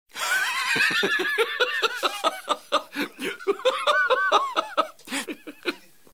rofl1.wav